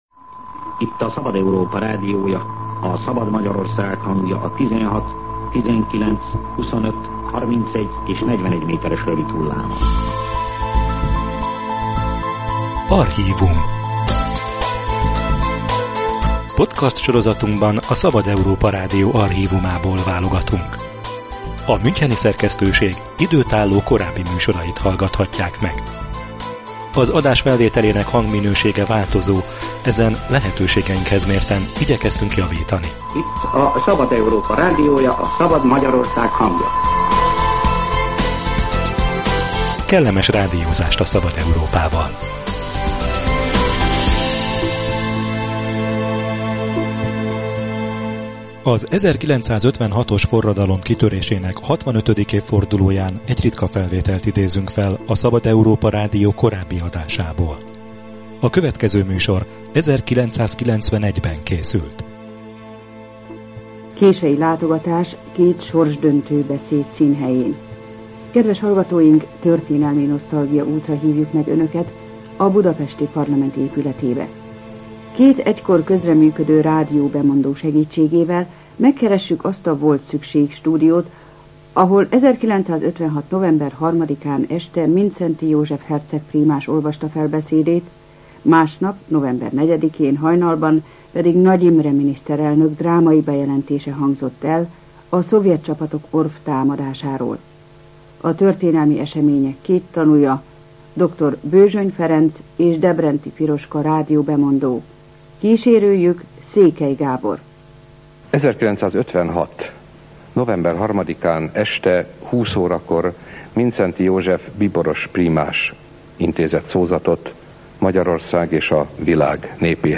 Az 1956-os forradalom kitörésének 65. évfordulóján egy ritka felvételt idézünk fel elődünk, a Szabad Európa Rádió korábbi adásából.